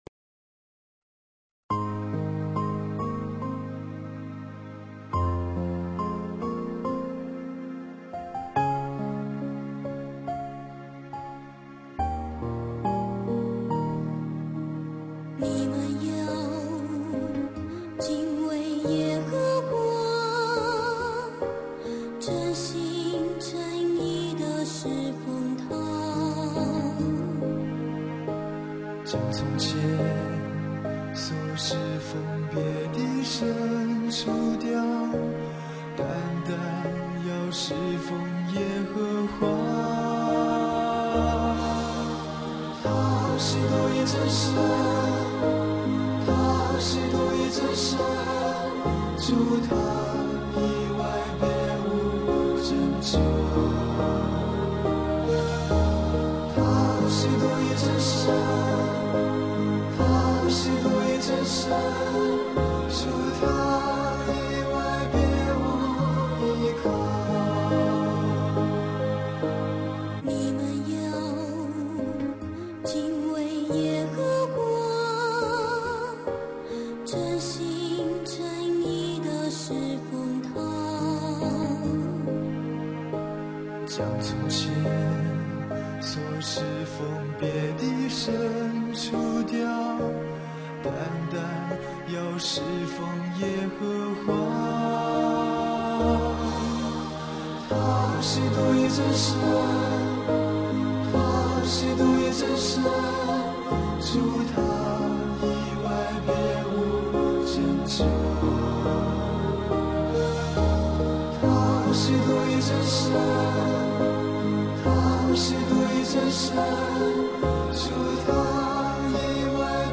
赞美诗 | 你们要敬畏耶和华